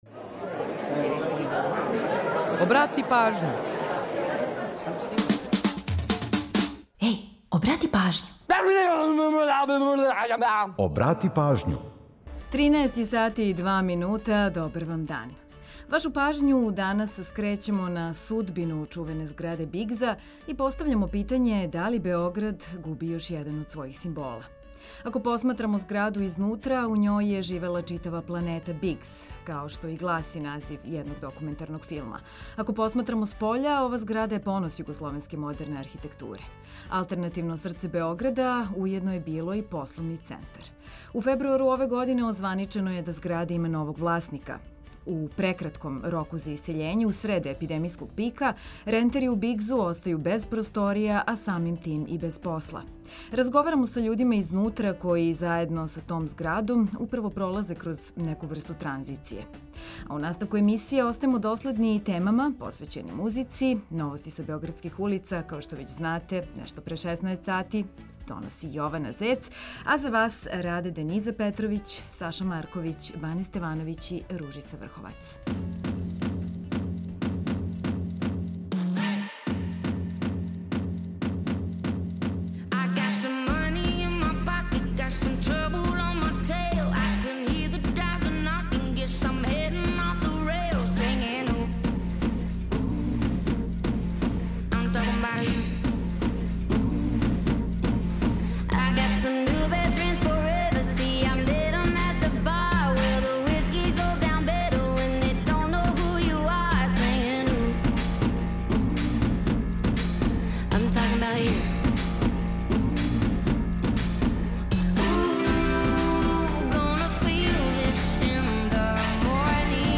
Разговарамо са људима „изнутра”